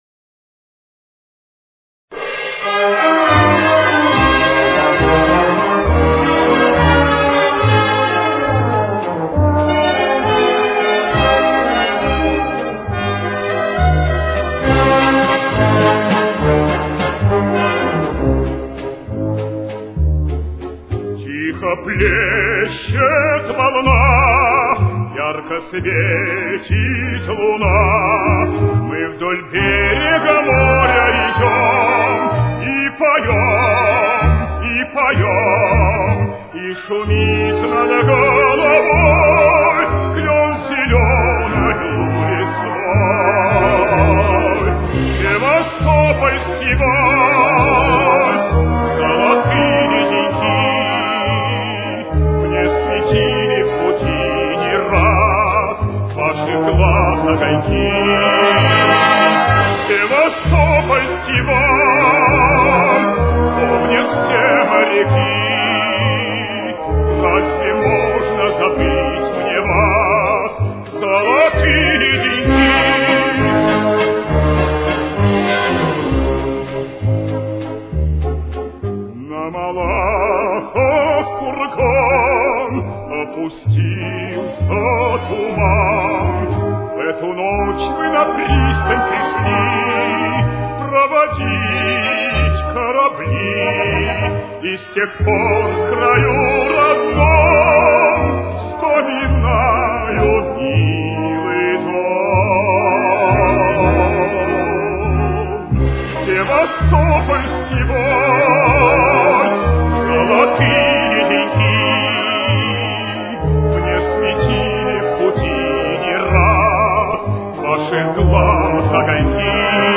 с очень низким качеством
До минор. Темп: 203.